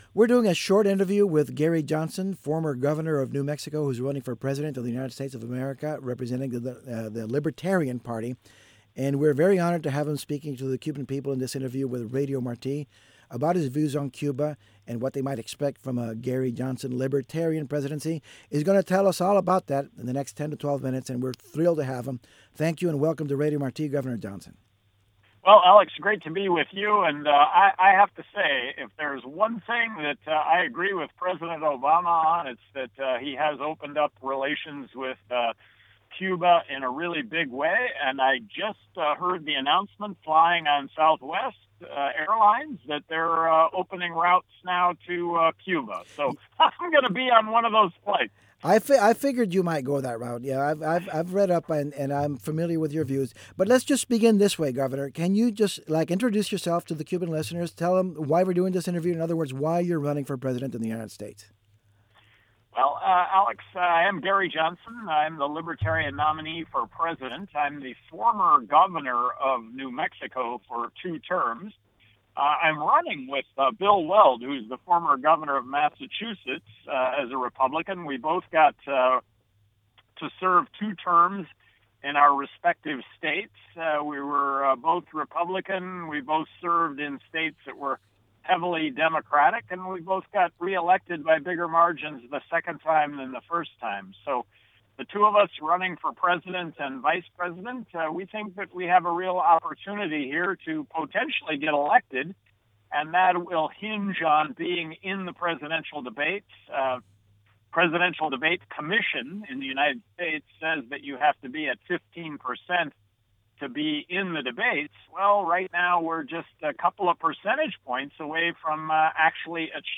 Entrevista a Gary Johnson